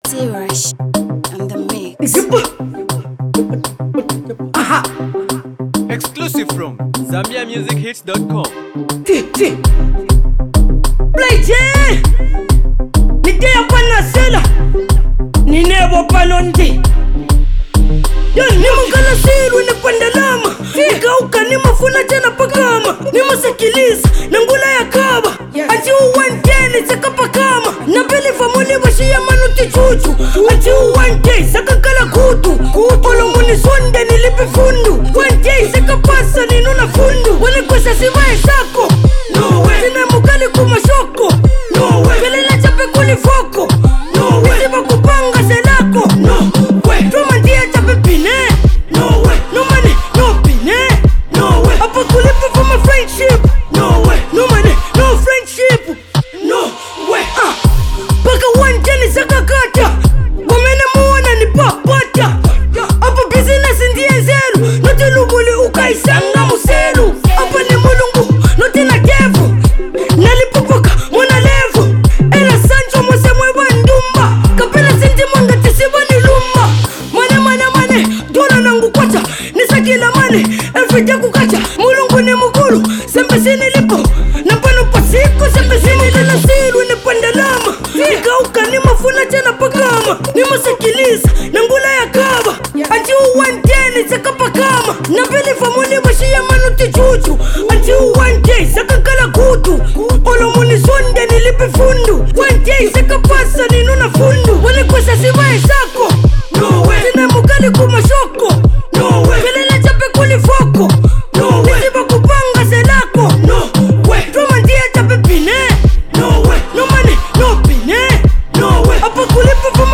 Talented Zambian Young Rapper